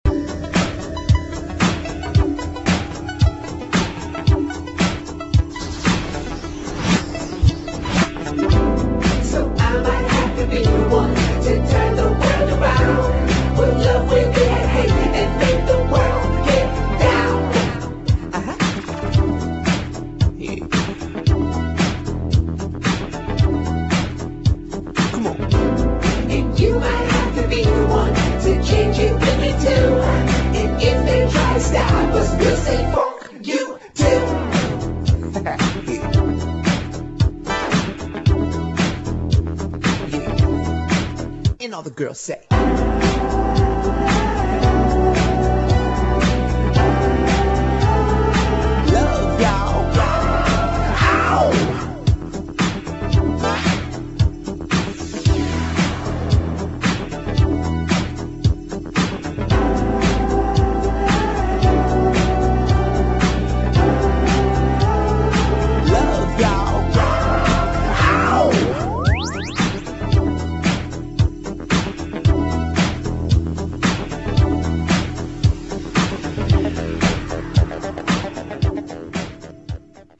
[ HOUSE | DISCO | ELECTRO ]